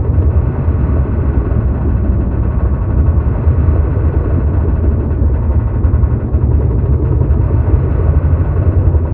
Sfx_creature_chelicerate_seatruckattack_loop_layer_metal_01.ogg